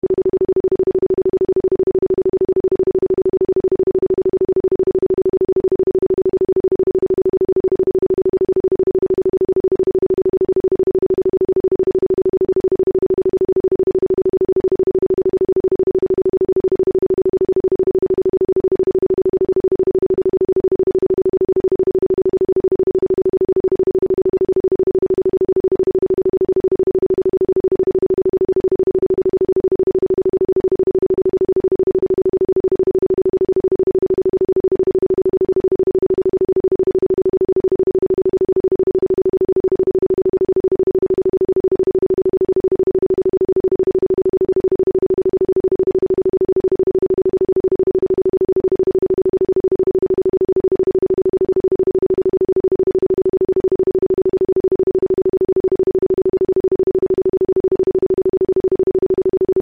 This pairing blends a low mid Fibonacci harmonic (377 Hz) with a low beta isochronic pulse (13 Hz). The carrier tone works in the lower emotional field and body core, while the isochronic pulse encourages mental clarity, alert calmness, and steady focus.